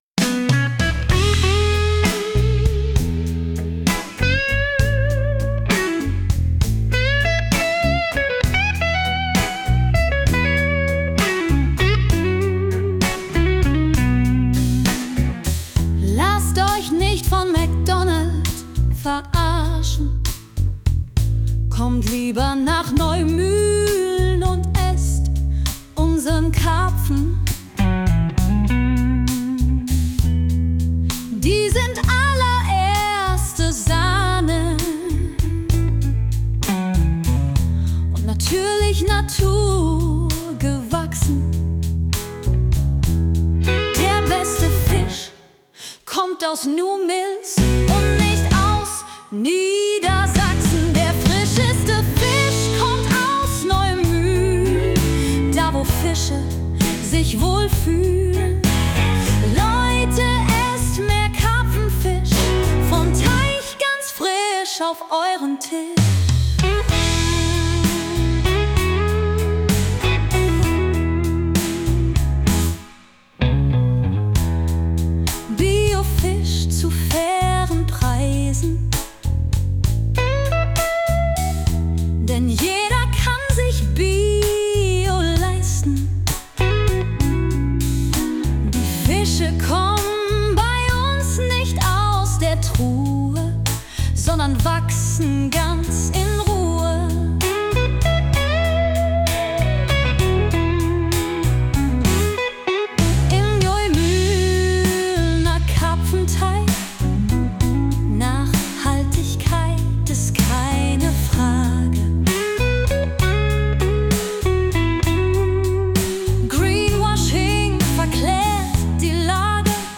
Der frischeste Fisch Blues.mp3